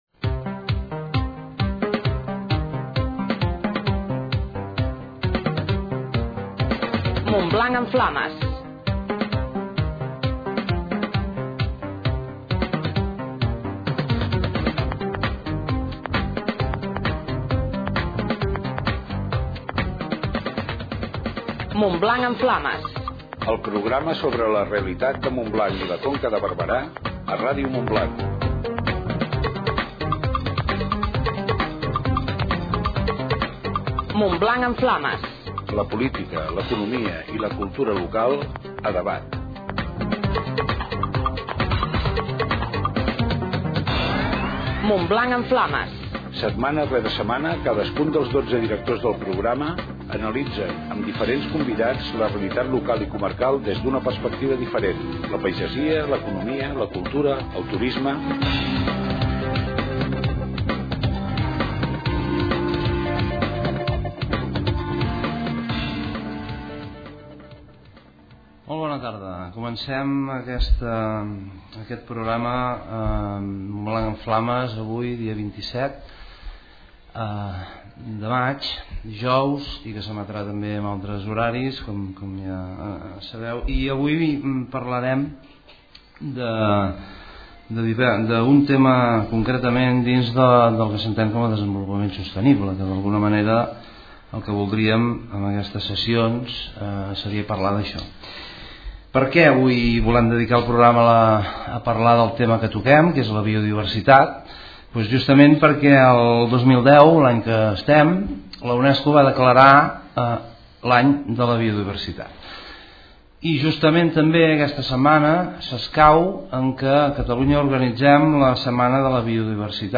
col·loqui sobre la Biodiversitat